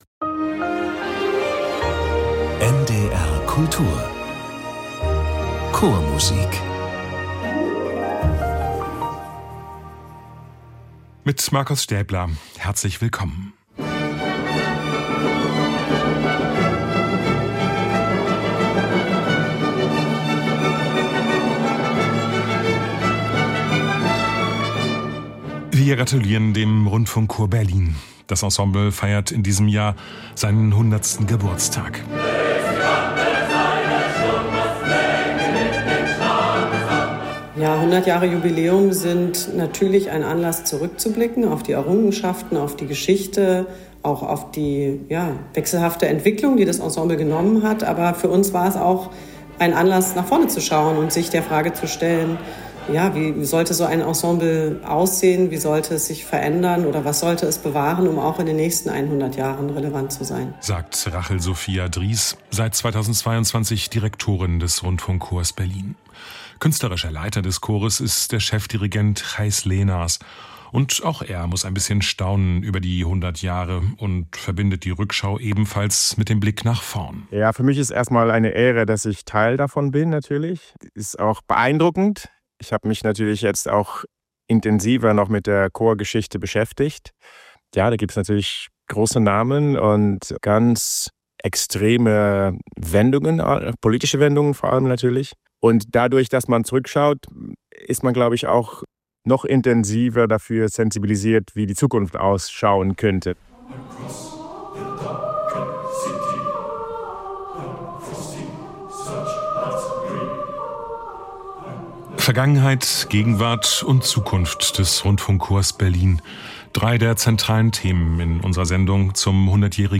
Chormusik aus allen Jahrhunderten, aus allen Ländern und Zeiten und Chöre aus aller Welt.